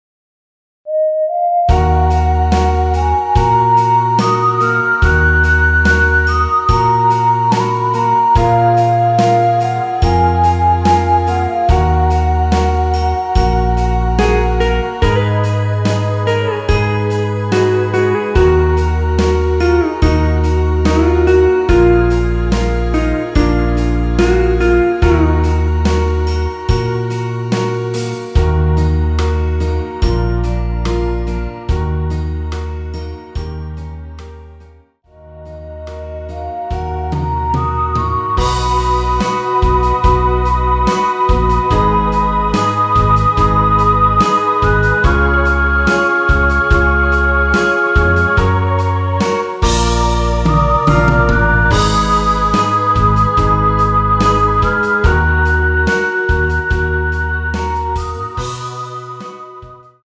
원키에서(+3)올린 MR입니다.
Ebm
앞부분30초, 뒷부분30초씩 편집해서 올려 드리고 있습니다.
중간에 음이 끈어지고 다시 나오는 이유는